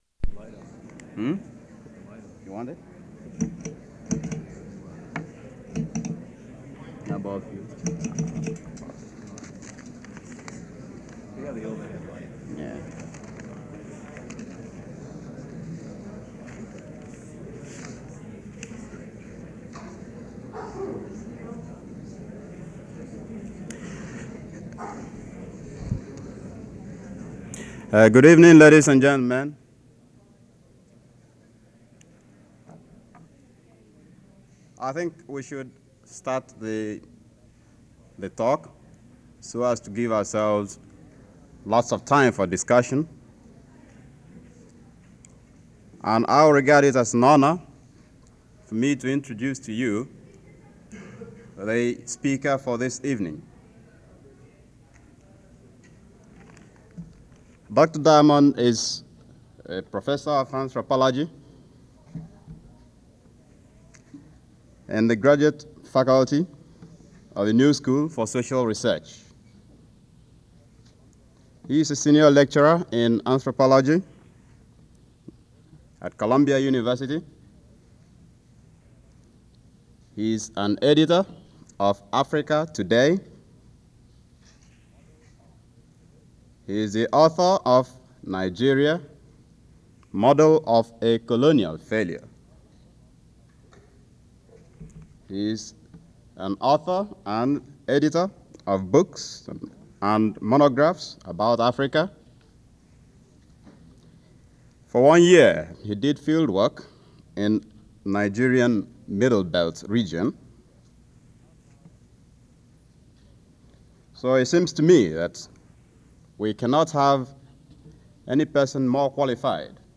Lecture, 1968
Original Format: Open reel audio tape